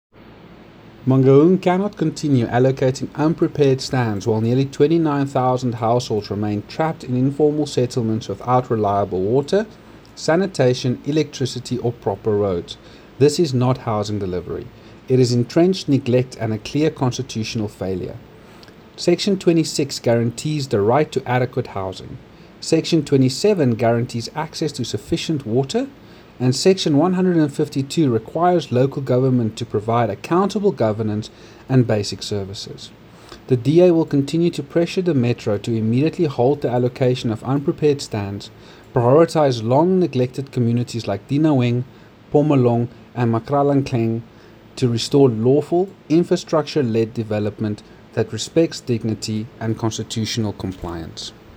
Afrikaans soundbites by Cllr Andre Snyman and